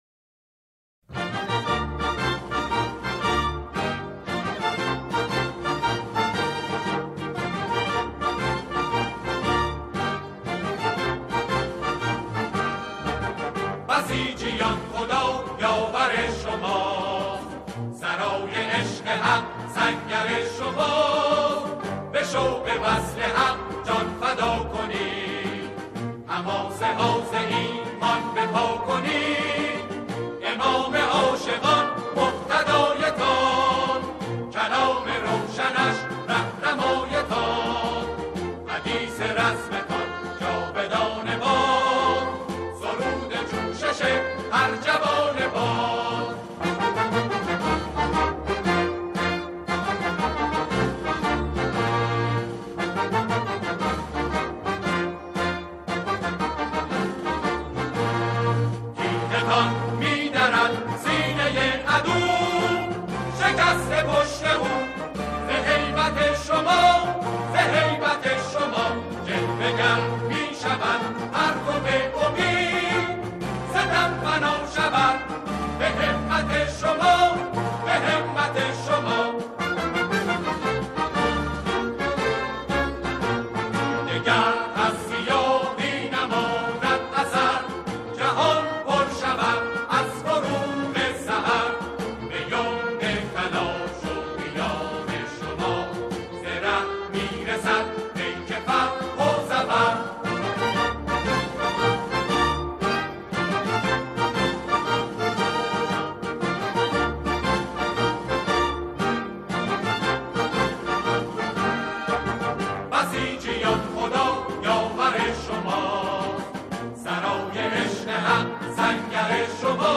سرودهای بسیج